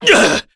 Chase-Vox_Damage_kr_03.wav